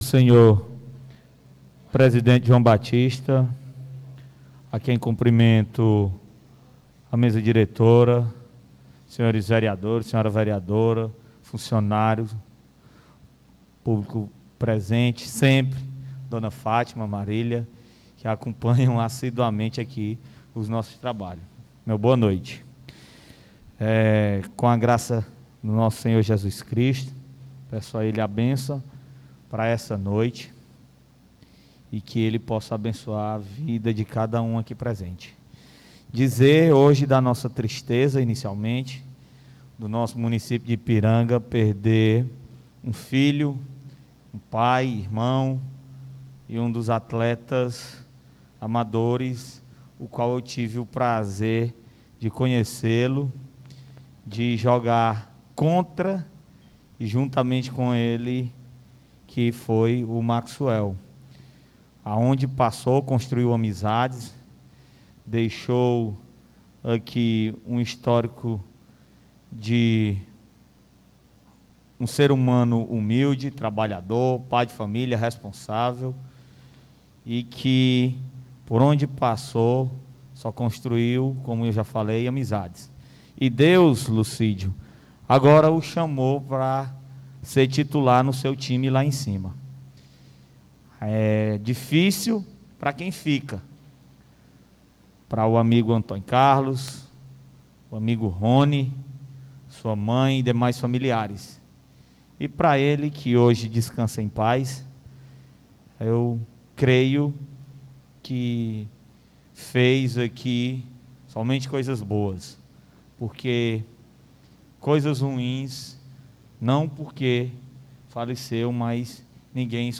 Pronunciamento Ver Rufino
2ª Sessão Ordinária